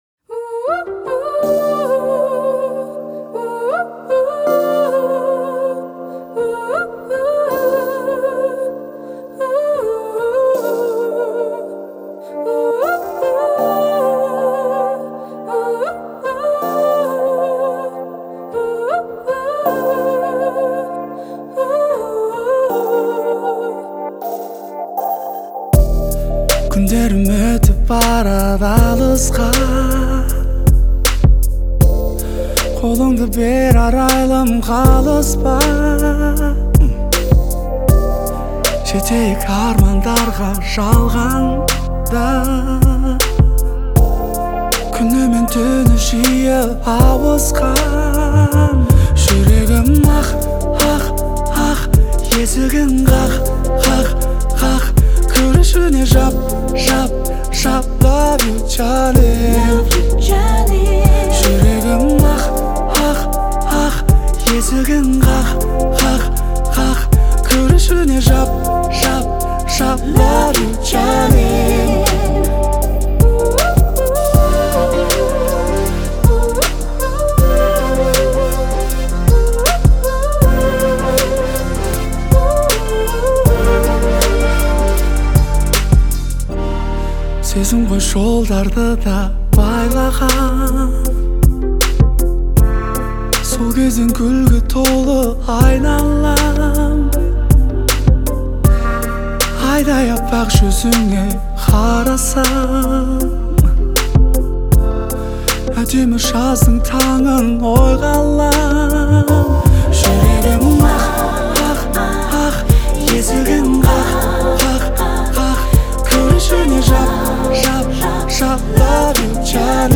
трогательная баллада